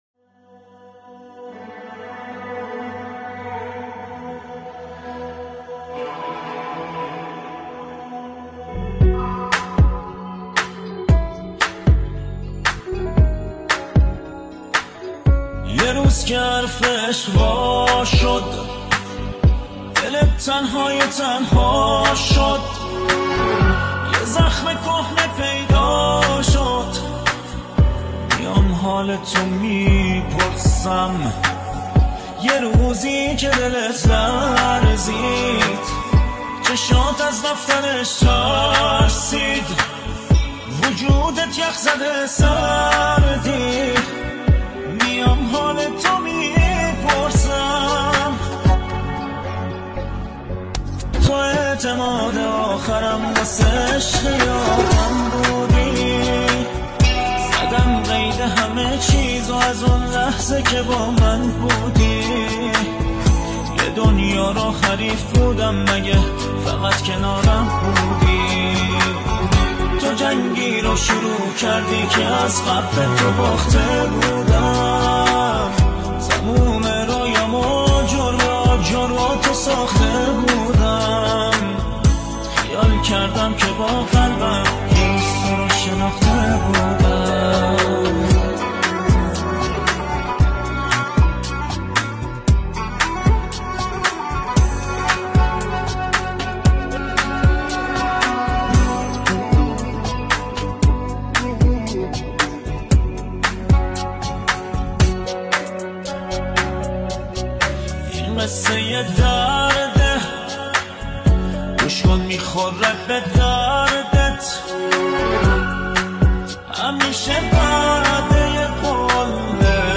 خواننده پاپ، آهنگساز، ترانه‌سرا و تنظیم‌کننده است.